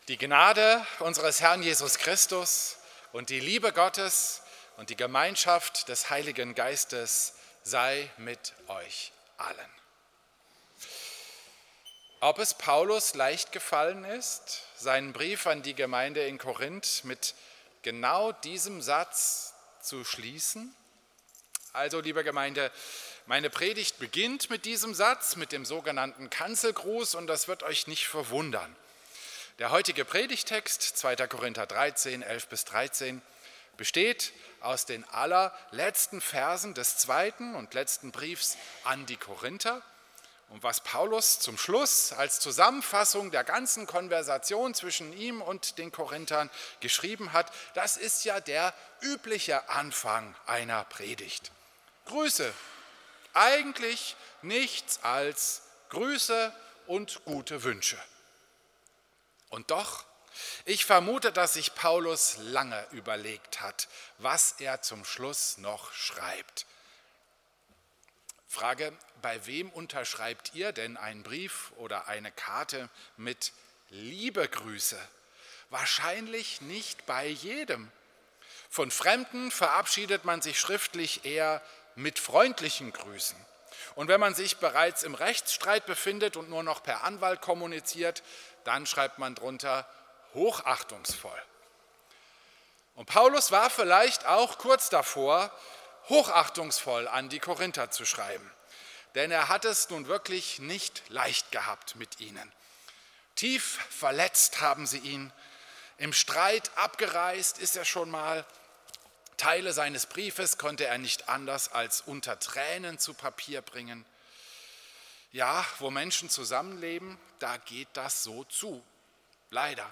Predigt zum Trinitatisfest
Christus-Pavillon Volkenroda, 15.